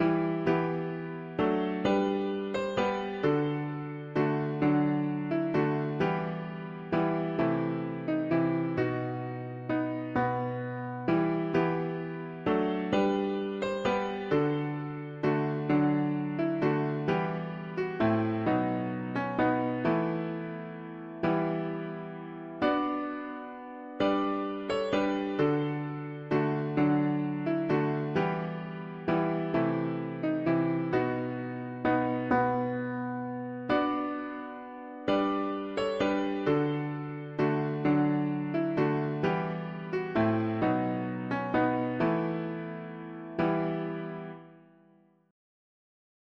This, this … english christian 4part winter
Music: traditional English melody Key: E minor